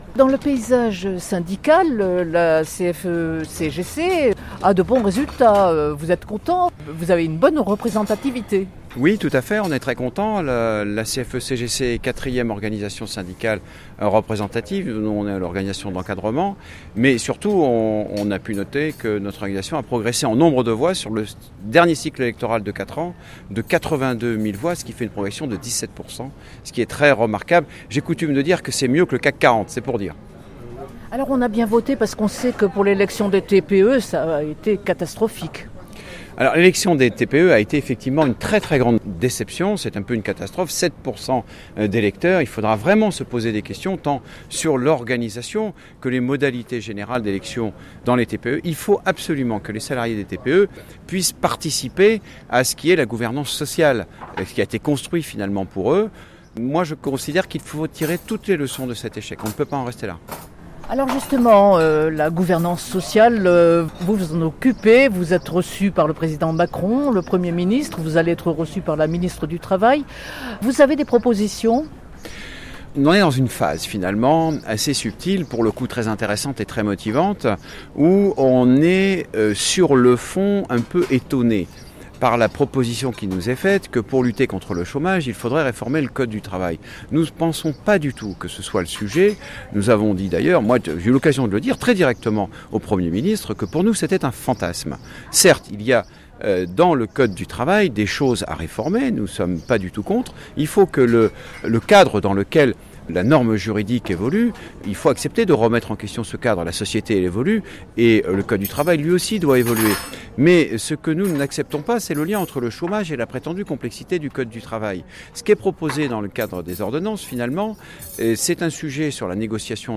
En marge de la conférence de presse entretien avec François Hommeril